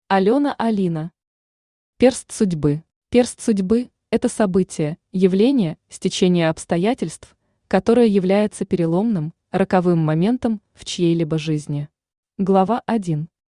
Aудиокнига Перст судьбы Автор Алёна Алина Читает аудиокнигу Авточтец ЛитРес.